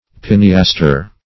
Pineaster \Pine`as"ter\, n.